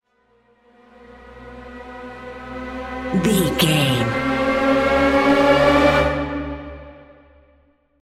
Atonal
tension
ominous
dark
suspense
eerie
synth
keyboards
pads
eletronic